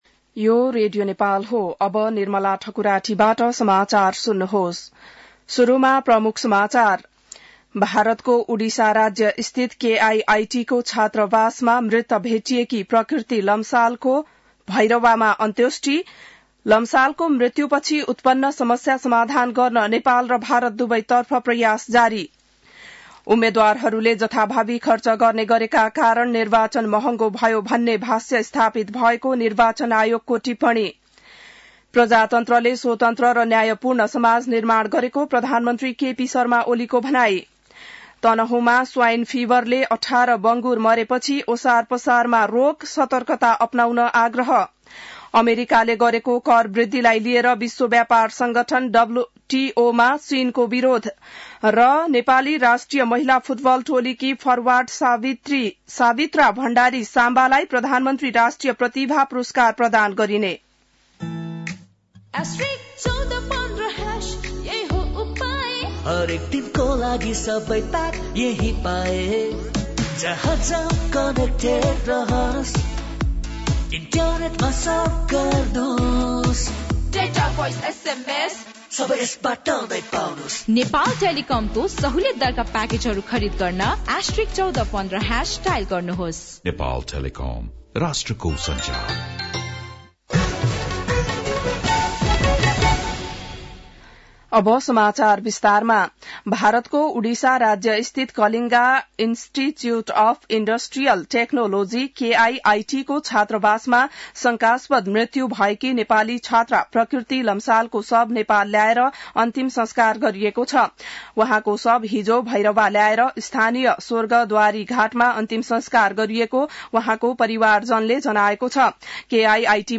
An online outlet of Nepal's national radio broadcaster
बिहान ७ बजेको नेपाली समाचार : ९ फागुन , २०८१